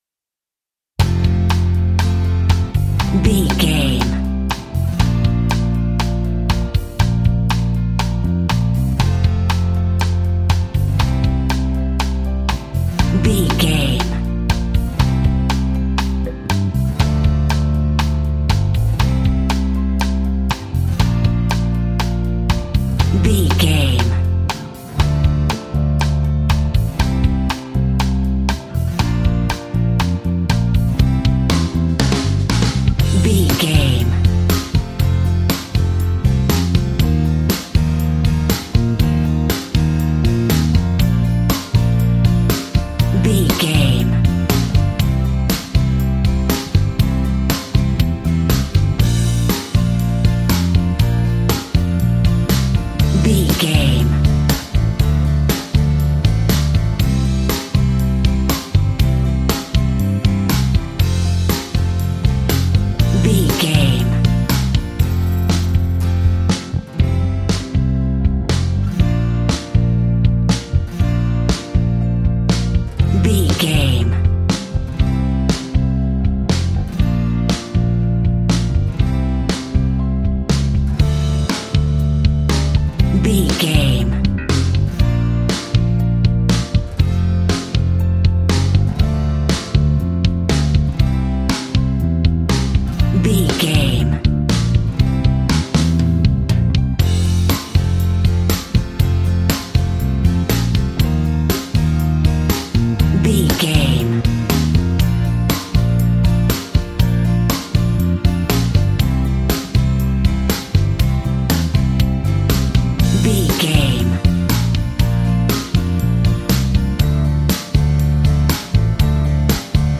Ionian/Major
pop rock
indie pop
fun
energetic
uplifting
instrumentals
upbeat
groovy
guitars
bass
drums
organ